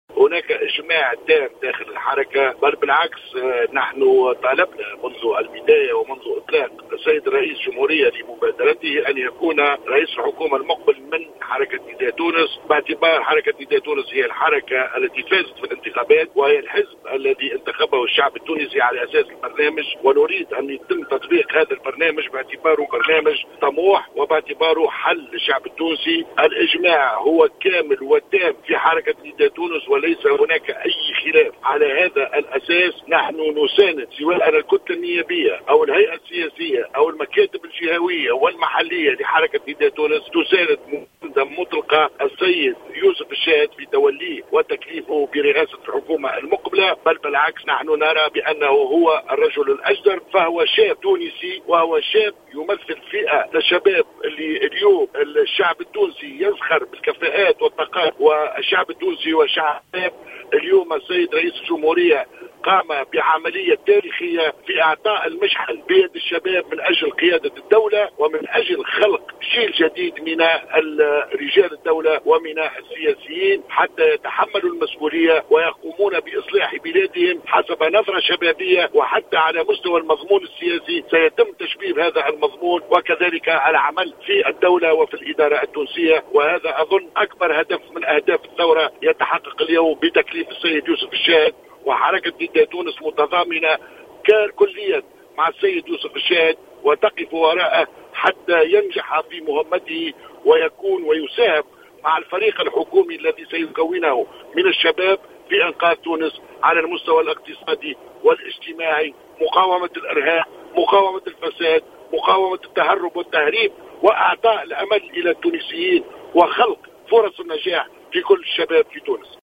اكد القيادي في حركة نداء تونس عبد العزيز القطي، في تصريح للجوهرة أف أم اليوم الخميس، أنه لا وجود لخلاف بين اعضاء حركة نداء تونس حول تكليف القيادي بالنداء يوسف الشاهد برئاسة الحكومة نافيا ما يروج عن رفض البعض من اعضاء الحركة لهذا التكليف.